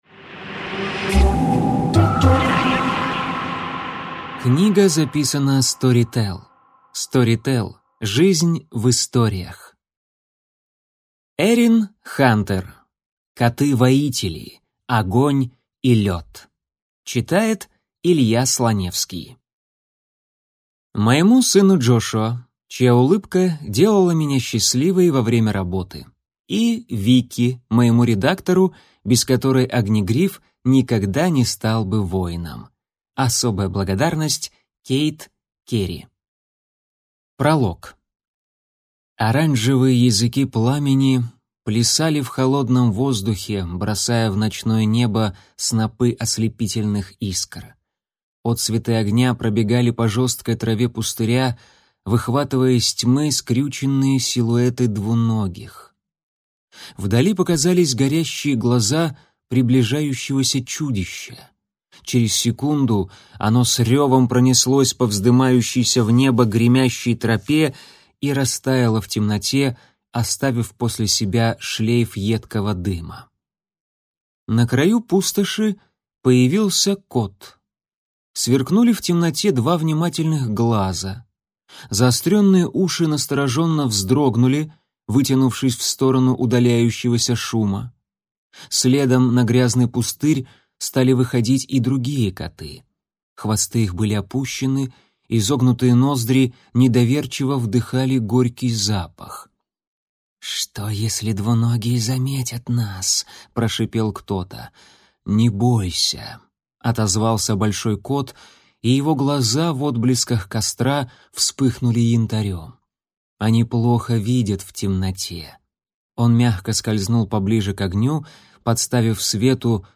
Аудиокнига Огонь и лед | Библиотека аудиокниг